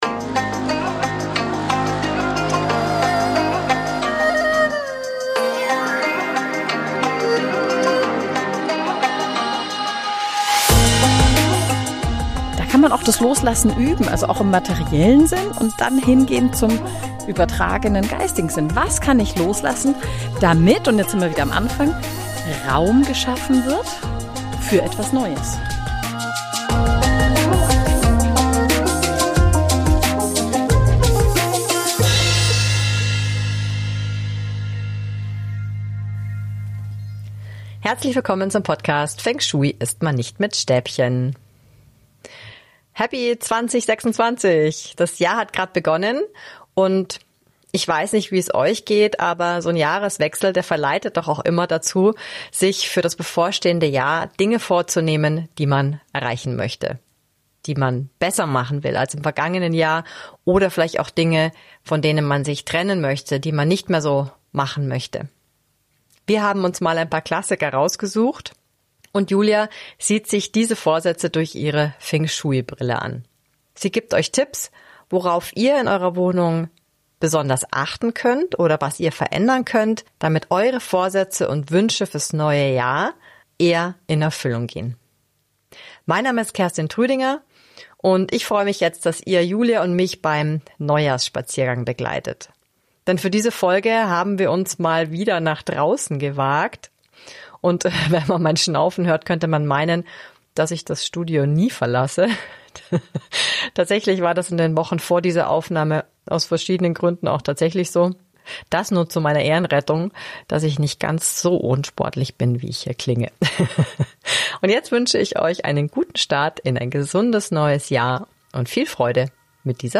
Aufgenommen wurde diese Episode nicht im Studio, sondern bei einem Neujahrsspaziergang. Frische Luft, ehrliche Gedanken – und ja, gelegentliches Schnaufen inklusive.